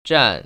[zhàn] 잔